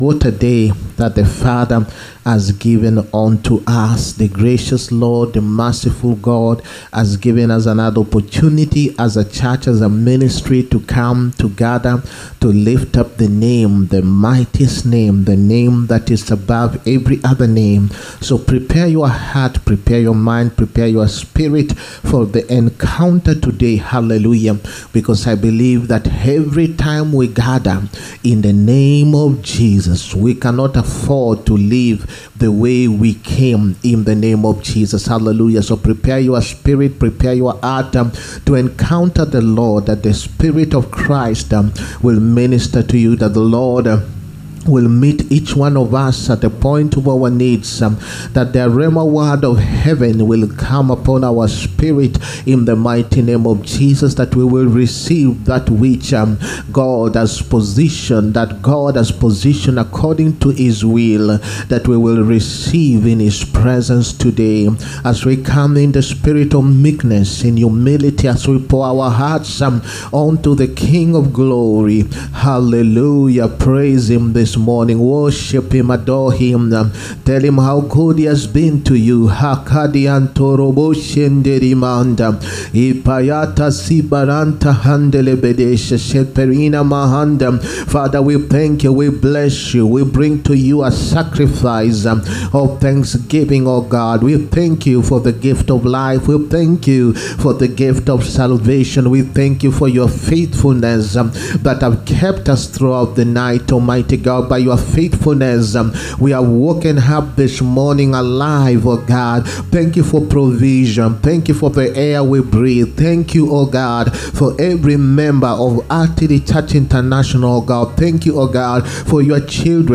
SUNDAY WORSHIP SERVICE. THE CALL TO PRIESTHOOD. 20TH APRIL 2025.